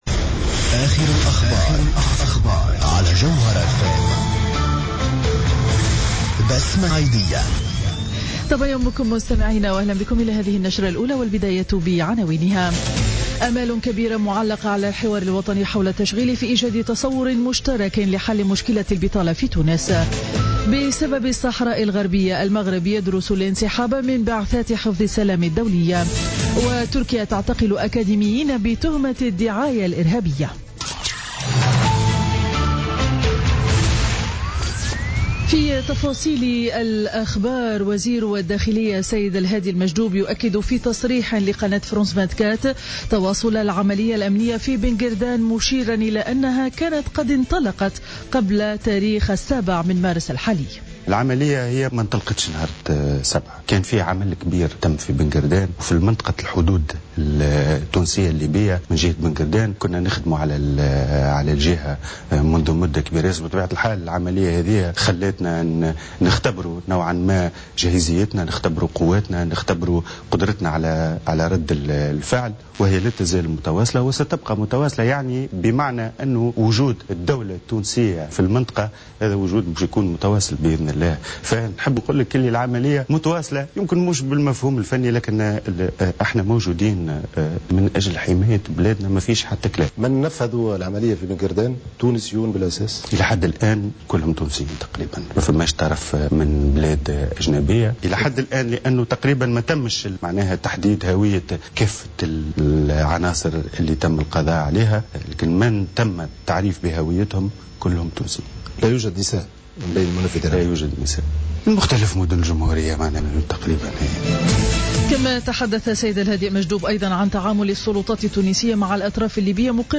نشرة أخبار السابعة صباحا ليوم الأربعاء 16 مارس 2016